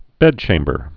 (bĕdchāmbər)